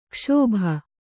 ♪ kṣōbha